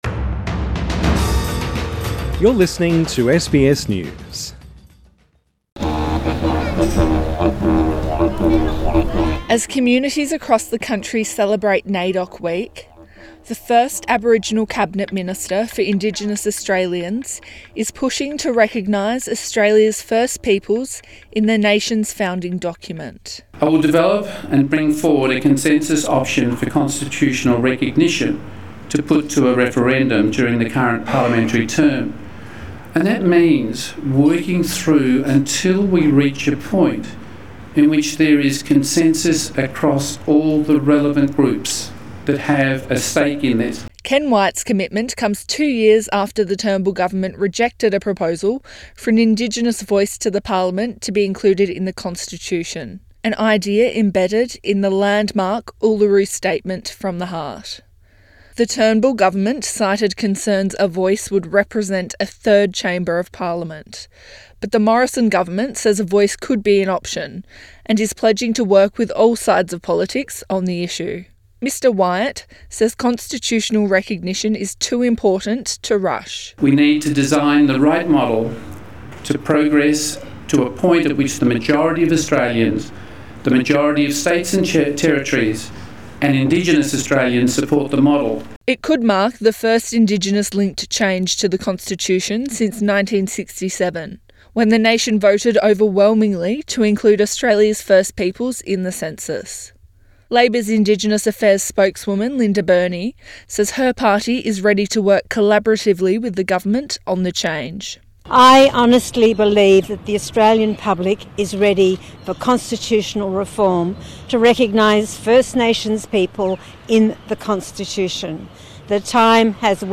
In his first major address since becoming the first Aboriginal Minister for Indigenous Australians, Ken Wyatt has pledged to bring forward a "consensus option" during this term of parliament.
Minister for Indigenous Australians Ken Wyatt at the National Press Club in Canberra Source: AAP